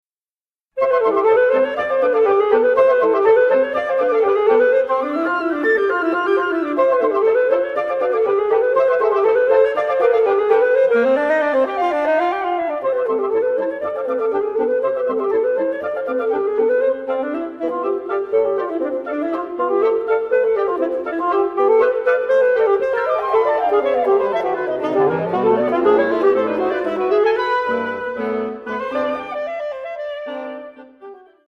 Alto Saxophone
for Saxophone and Woodwind Quintet